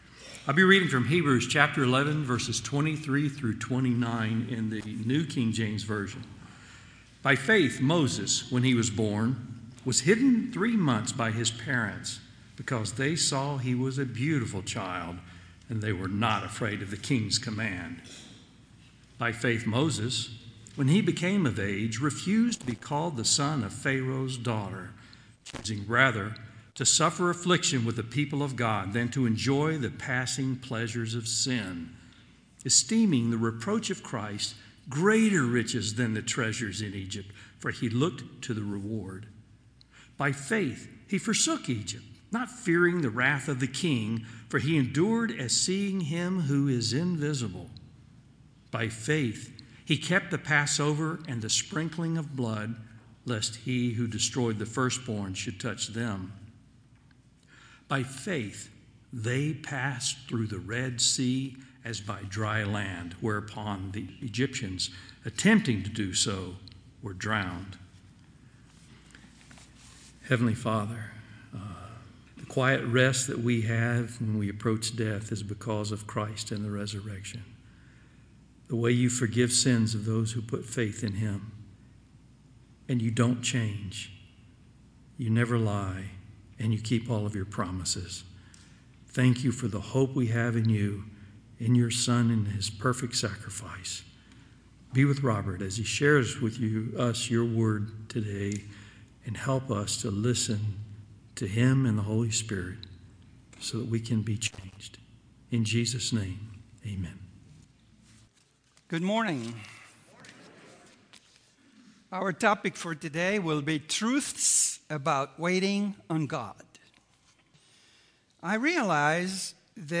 Sermons - Community Bible Chapel, Richardson, Texas